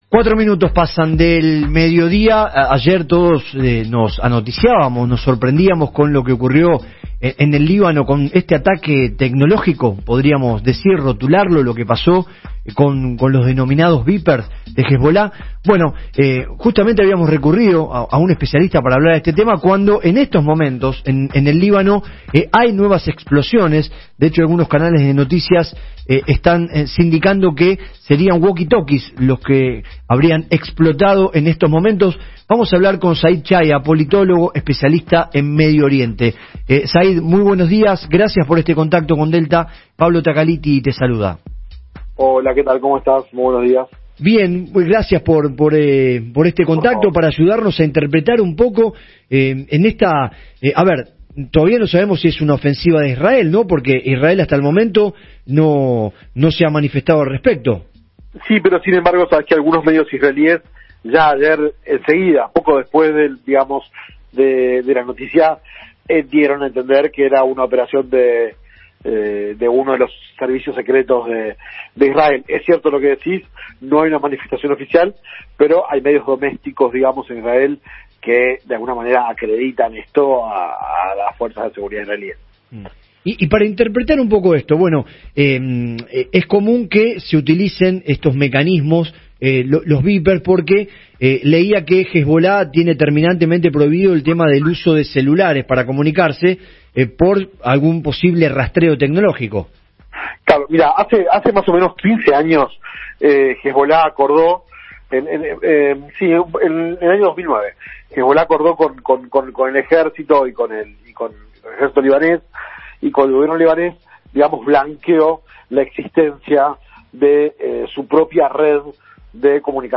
Entrevista
Entrevista en radio Delta